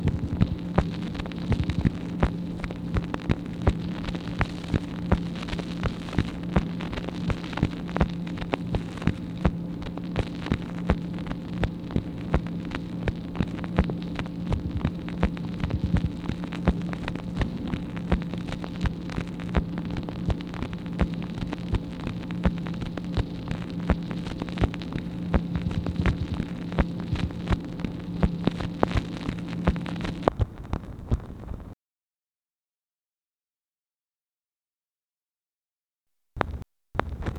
MACHINE NOISE, April 29, 1964
Secret White House Tapes | Lyndon B. Johnson Presidency